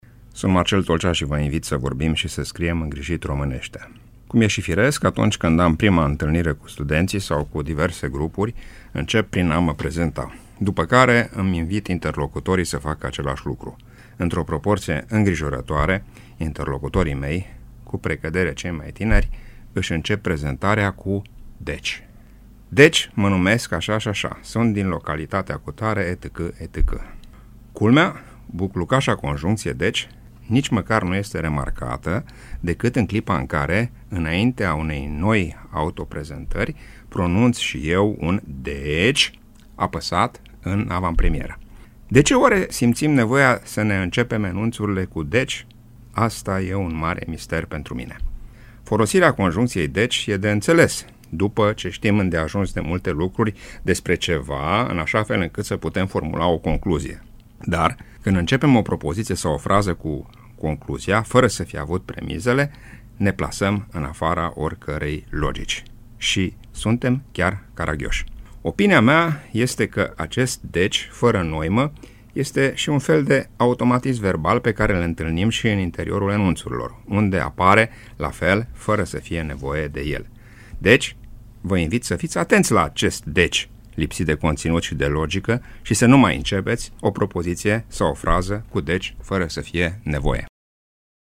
Când folosim conjuncţia „deci”? (rubrică difuzată în 5 august 2015)